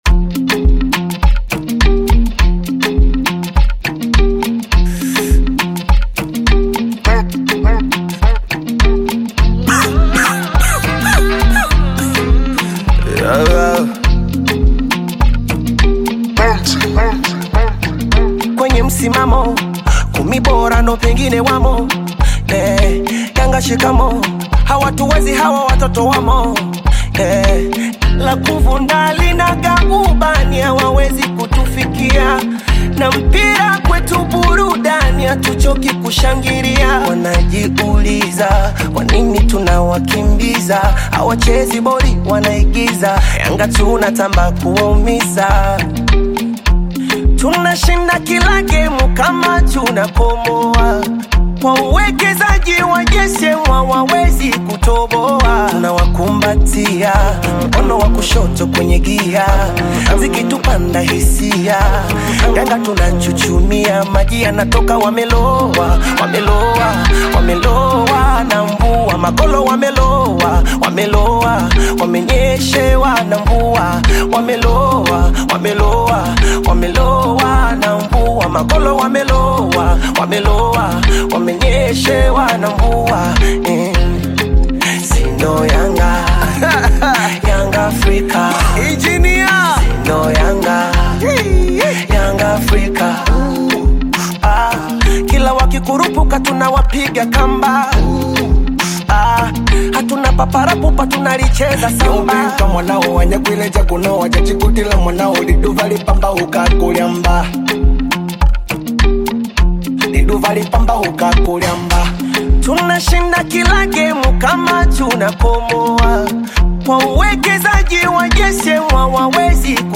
bongo flava
African Music